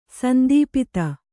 ♪ sandīpita